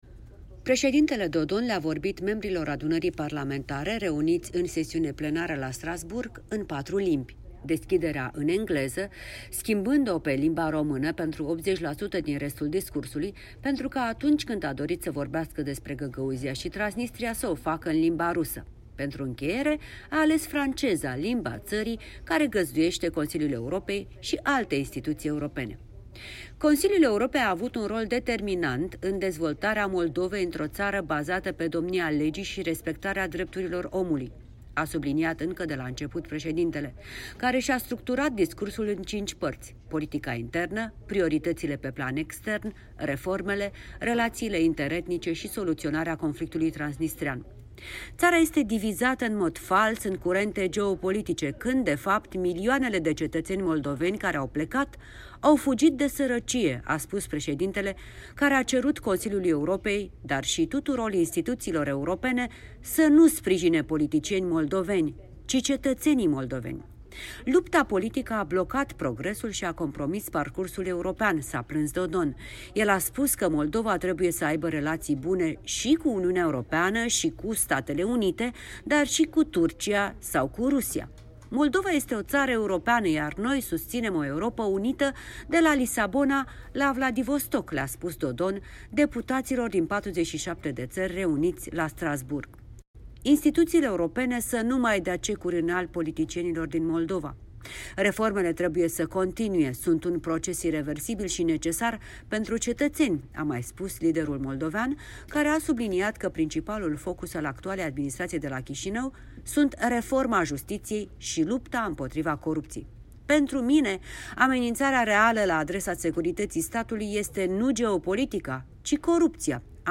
Președintele moldovean Igor Dodon vorbind în Adunarea Parlamentară a Consiliului Europei. 29 ianuarie 2020